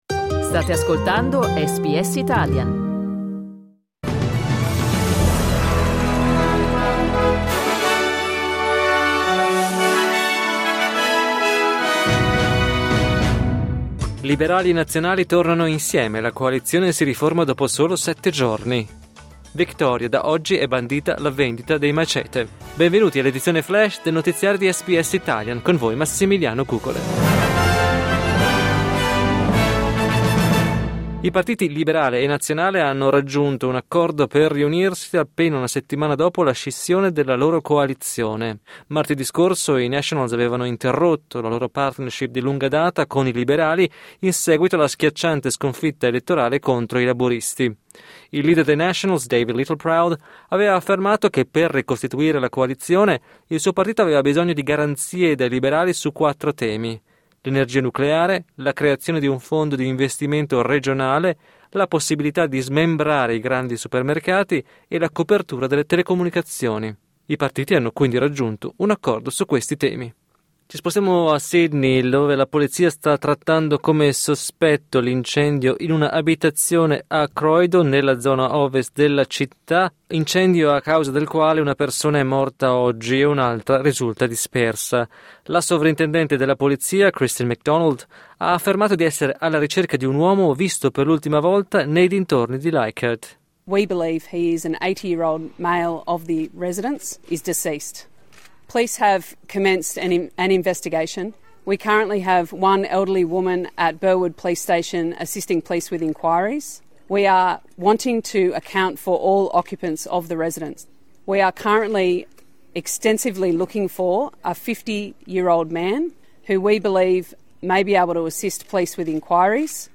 News flash mercoledì 28 maggio 2025